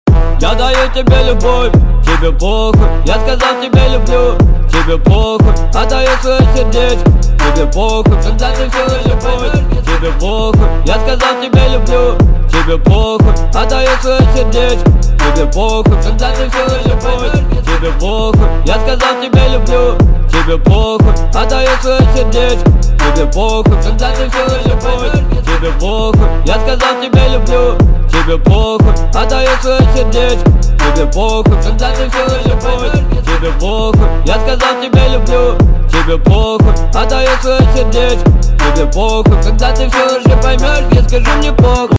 • Качество: 128 kbps, Stereo